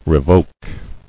[re·voke]